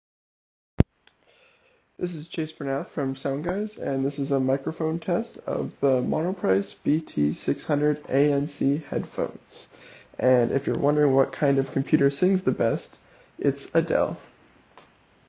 Monoprice-BT-600ANC-Mic-test.m4a